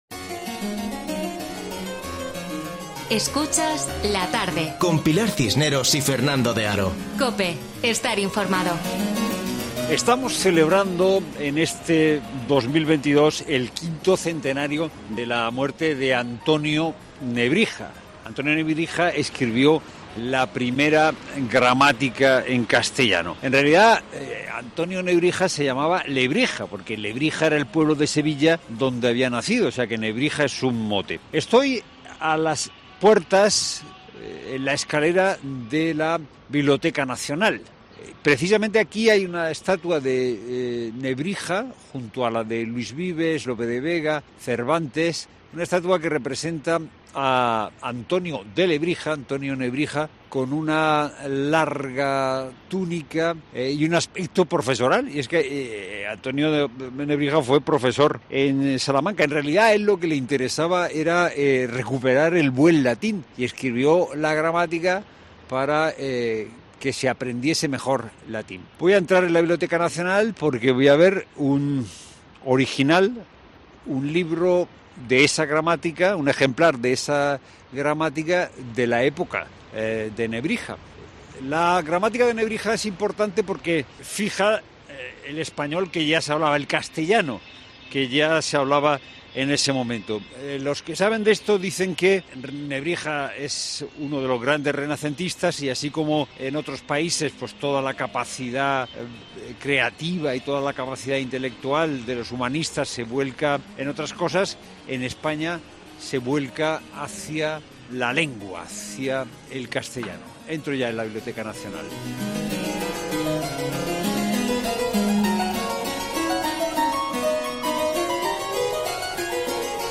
Visita por la Biblioteca Nacional para conocer las primeras gramáticas de Antonio Nebrija, del que se cumple el quinto centenario de su muerte este año...